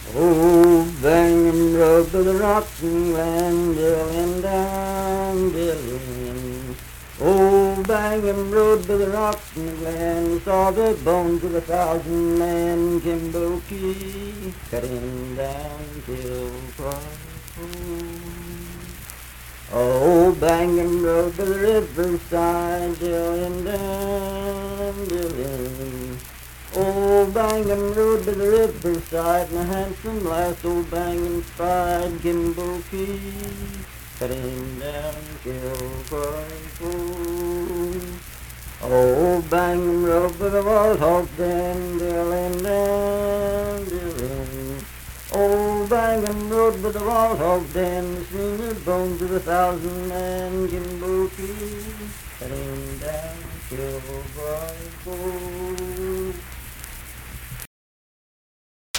Unaccompanied vocal music
Verse-refrain 3(6w/R).
Voice (sung)